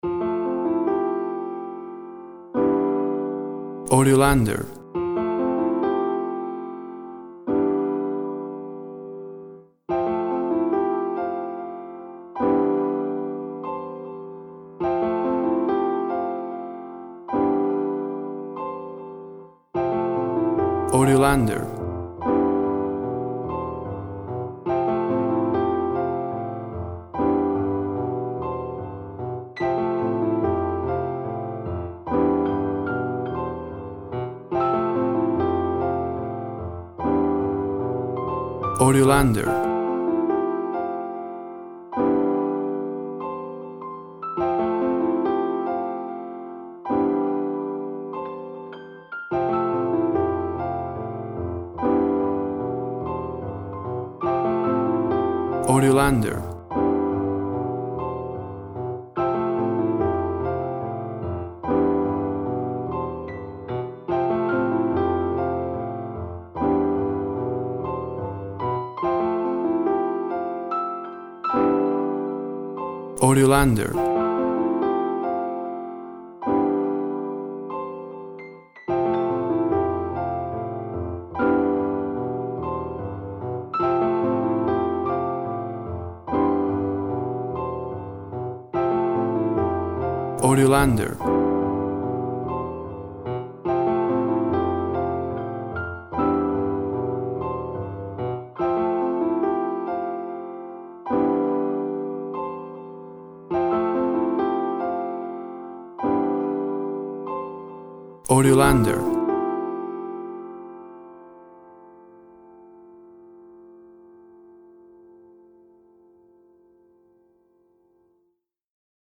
Piano song.
Tempo (BPM) 70